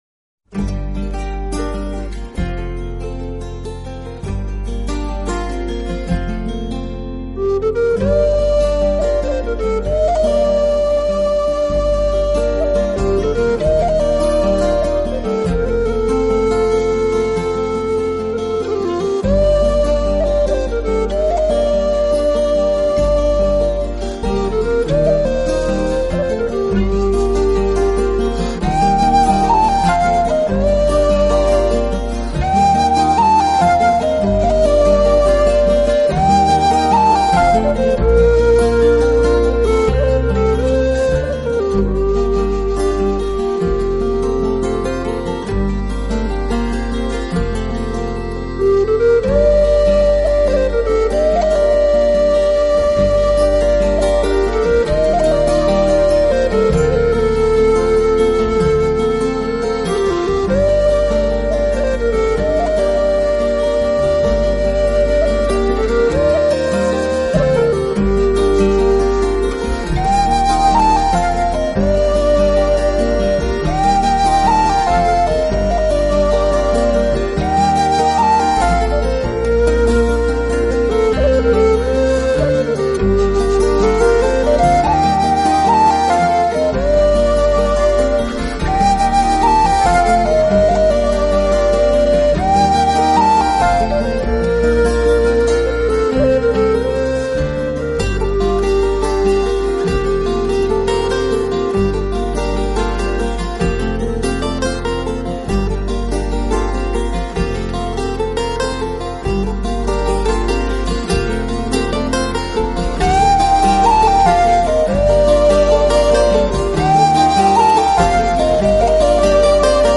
【风笛专辑】